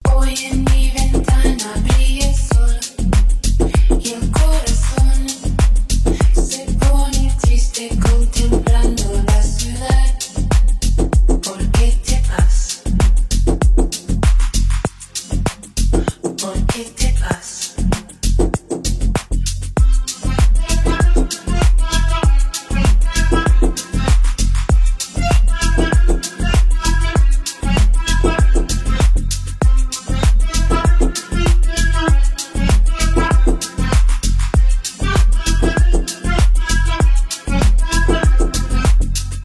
танцевальные
ремиксы
deep house